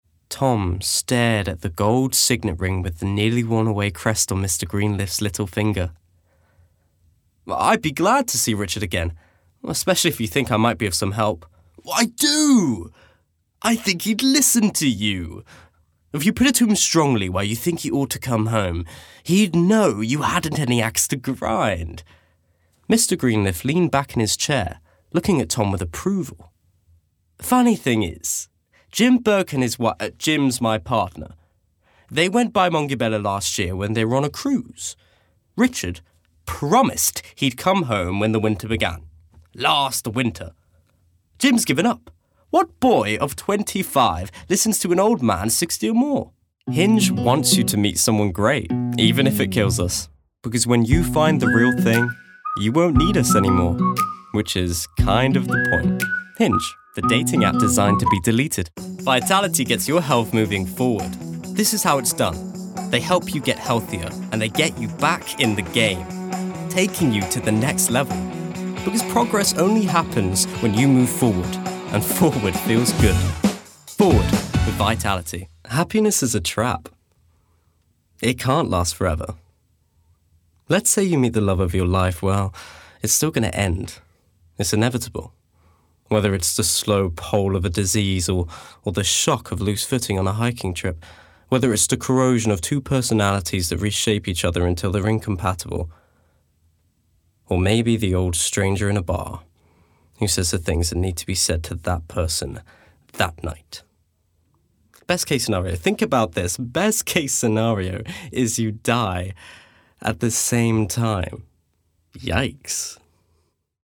British
RP
Voicereel: